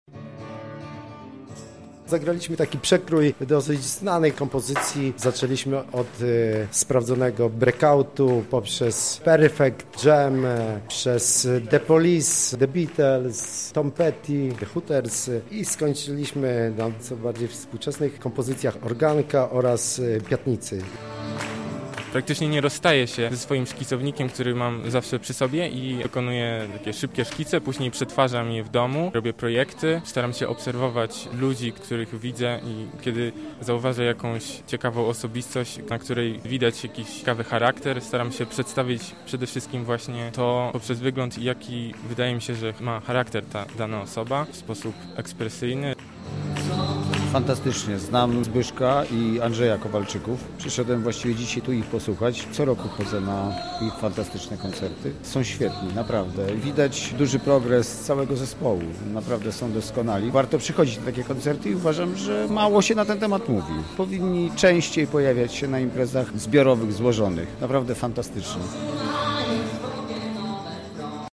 O tym, co się działo na festiwalu i towarzyszących temu koncertach pytali nasi reporterzy: